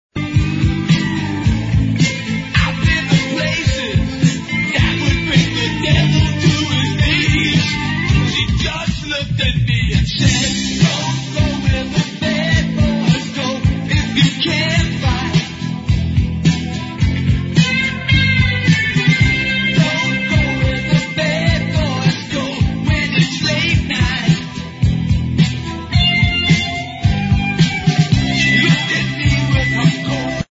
lead vocals, drums, percussion
(slide and acoustic guitars)
piano, keyboards